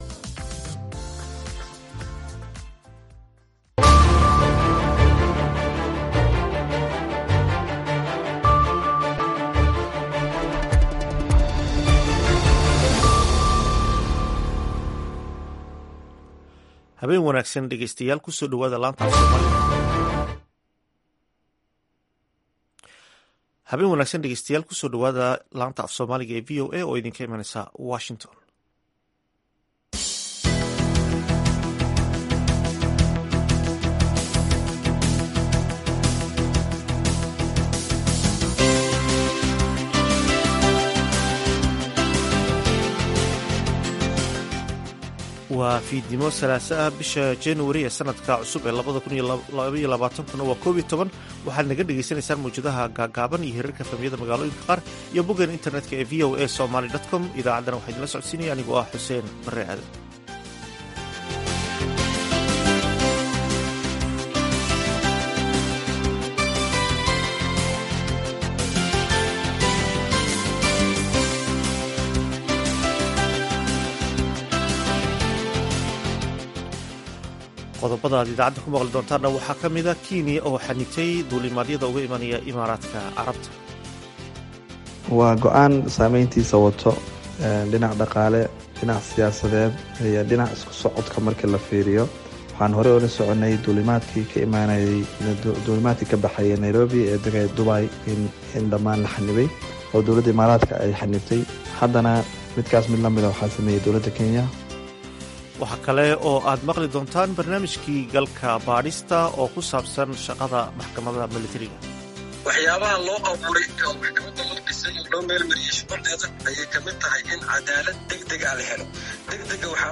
Idaacadda Fiidnimo ee Evening Edition waxaad ku maqashaan wararkii ugu danbeeyey ee Soomaaliya iyo Caalamka, barnaamijyo iyo wareysiyo ka turjumaya dhacdooyinka waqtigaasi ka dhacaya daafaha Dunida.